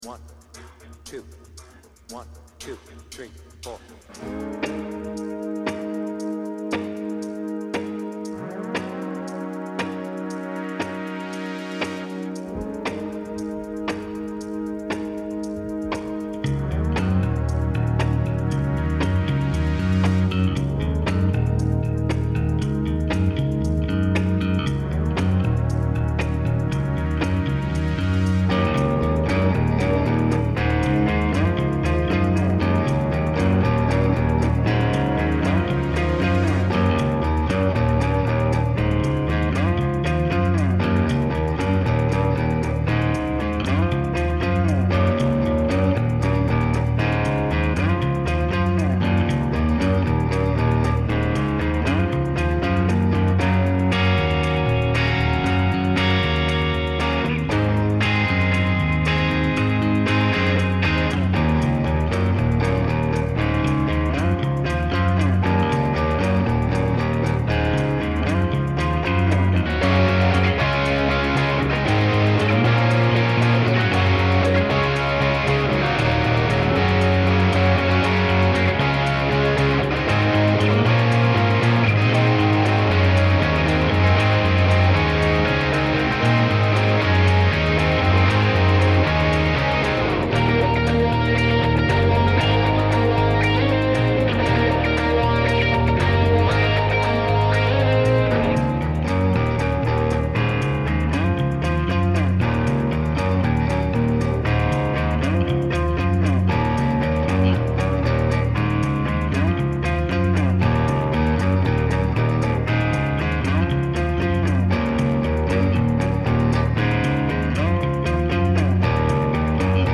BPM : 116
Tuning : Eb
Without vocals